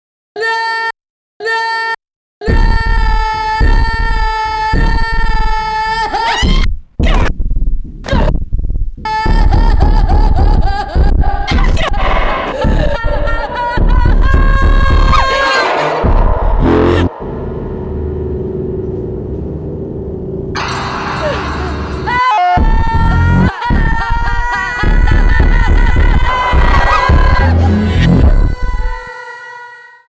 саундтрек
ужасы , tik-tok , страшные , пугающие , крики , громкие
жесткие , басы